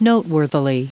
Prononciation du mot noteworthily en anglais (fichier audio)
noteworthily.wav